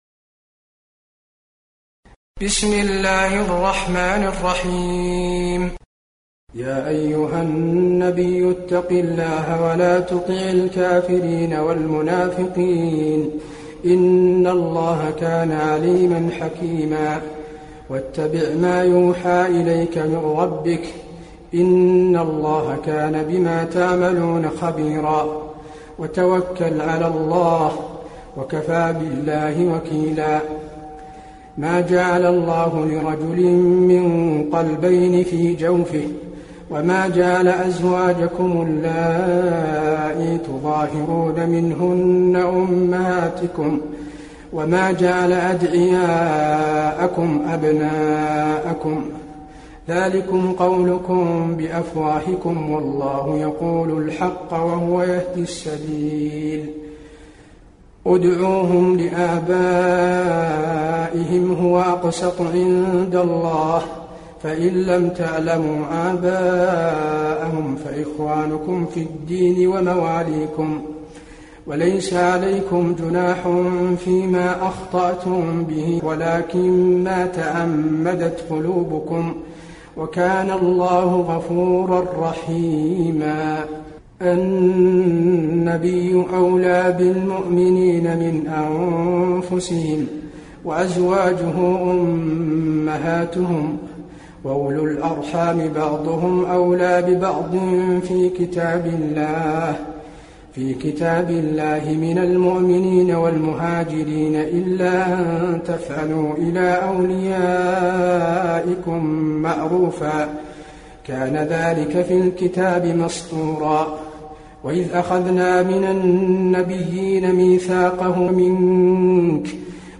المكان: المسجد النبوي الأحزاب